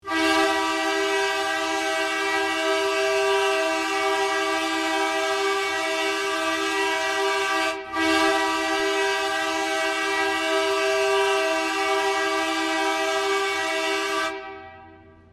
edmonton oilers goal horn 2018 Meme Sound Effect
edmonton oilers goal horn 2018.mp3